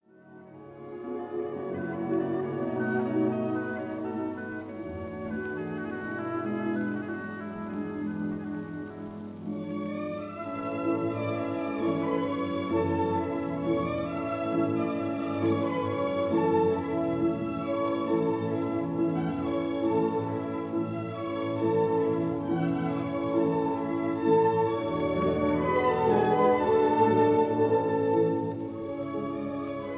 This is a 1946 recording
A major, Allegretto grazioso